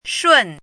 怎么读
shùn